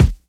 Index of /90_sSampleCDs/Best Service Dance Mega Drums/BD HIP 02 B